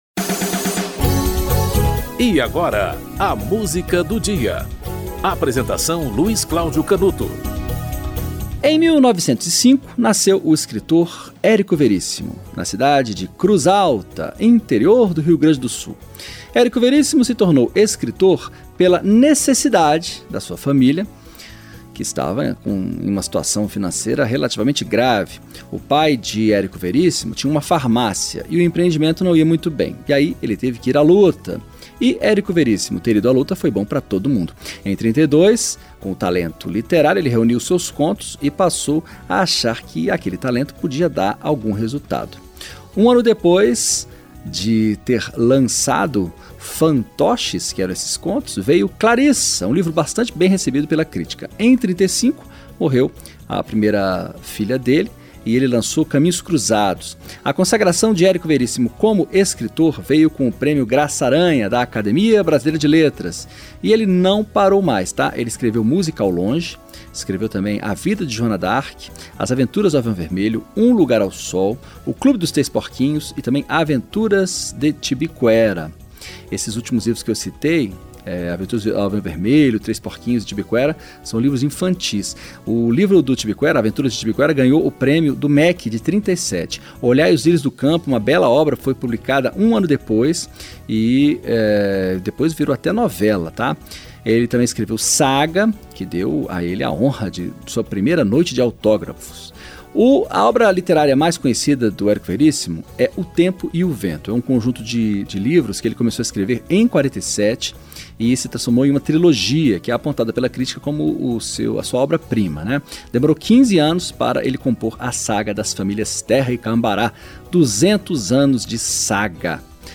Coro, Danilo Caymmi e Tom Jobim - Passarim (Tom Jobim)
O programa apresenta, diariamente, uma música para "ilustrar" um fato histórico ou curioso que ocorreu naquele dia ao longo da História.